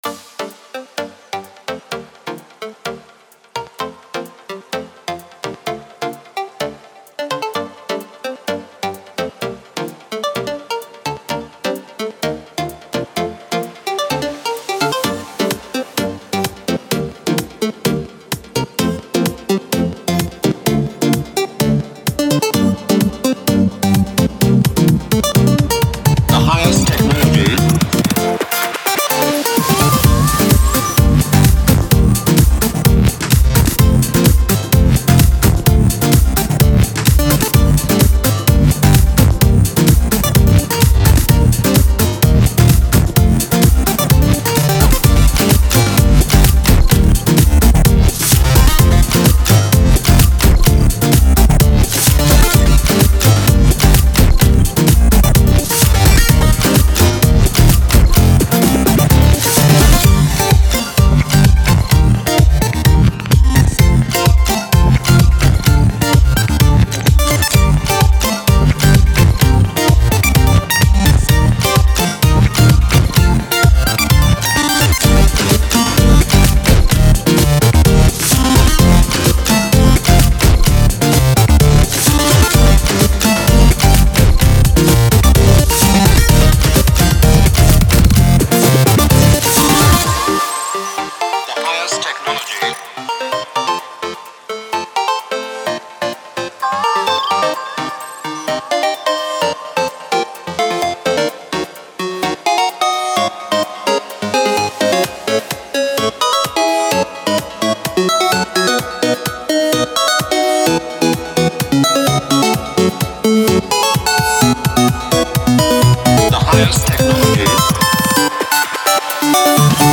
something akin to electro glitch house...?
House